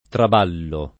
traballo [ trab # llo ]